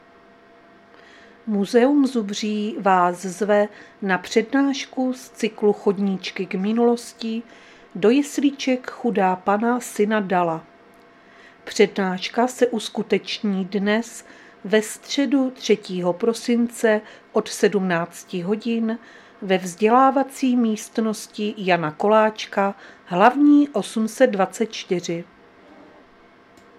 Záznam hlášení místního rozhlasu 3.12.2025